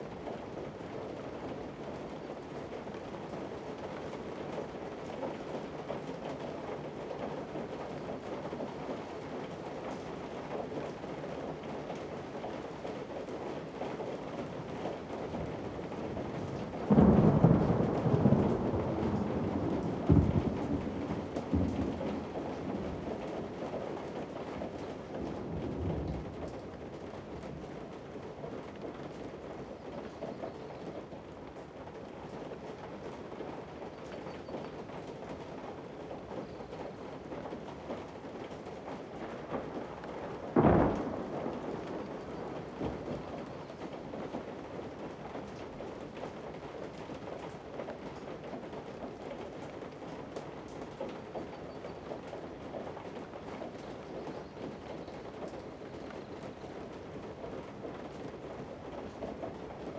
Inside Day Storm.ogg